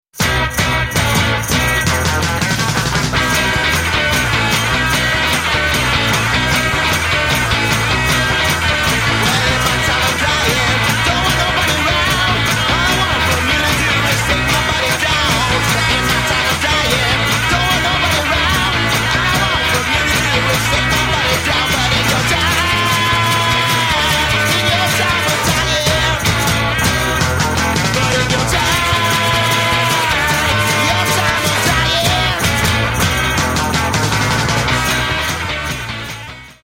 Grandissimo suono e spettacolare performance.
bass player